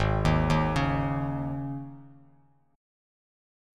G#sus4 chord